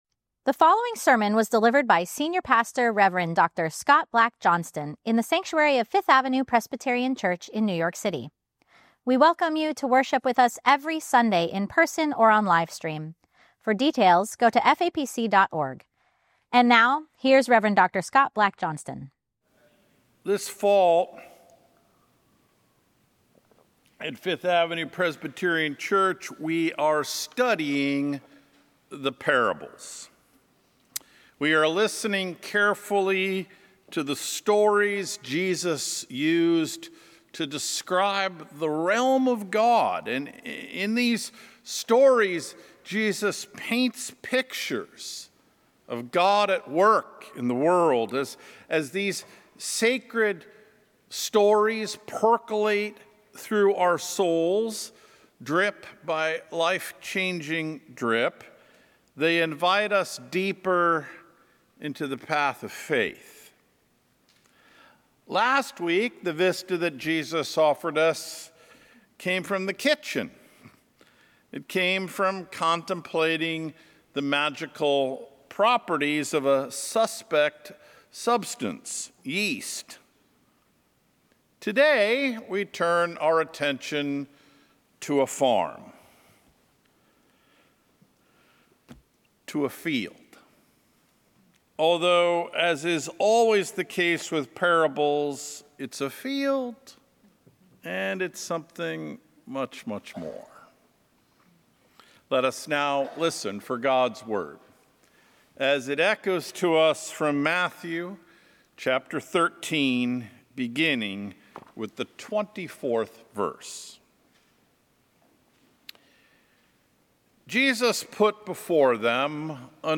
Sermon: “Weeds”